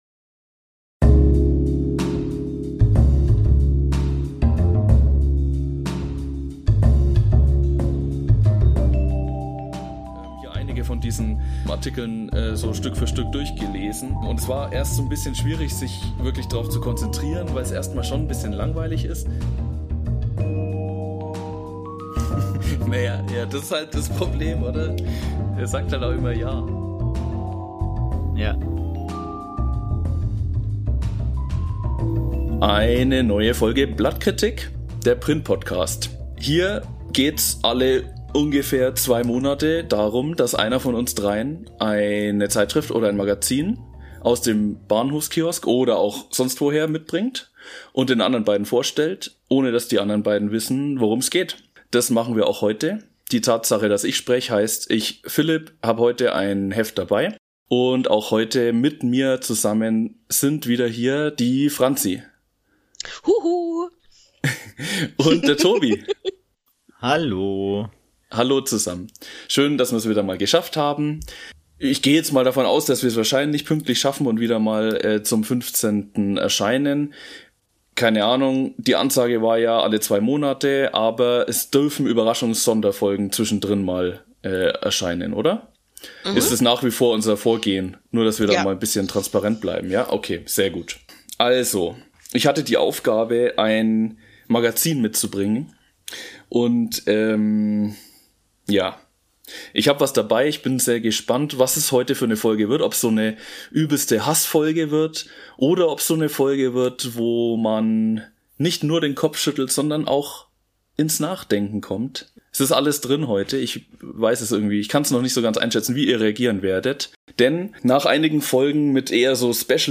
Das findet das Trio in dieser Episode heraus.